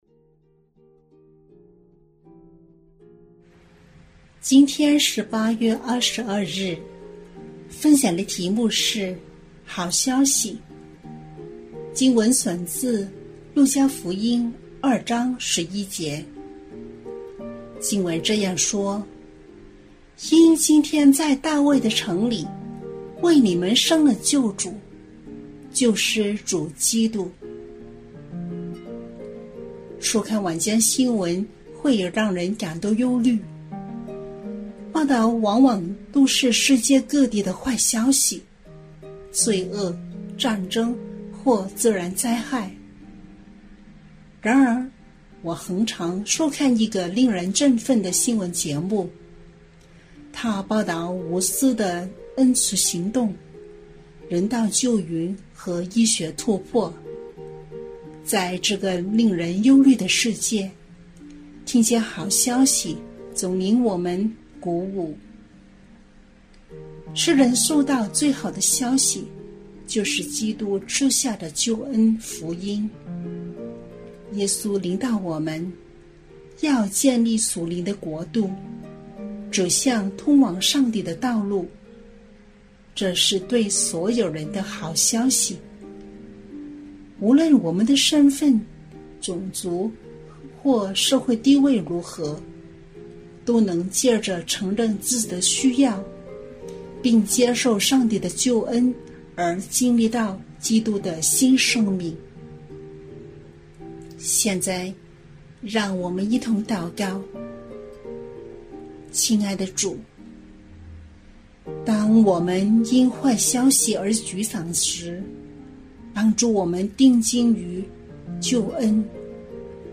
普通話2024年8月22日好消息